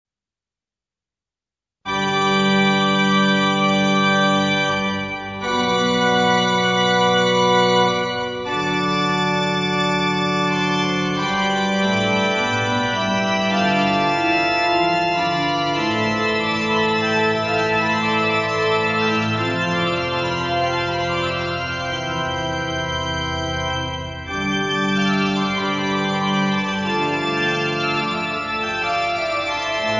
organo